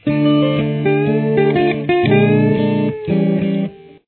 Here is what it sounds like with the same chords as above :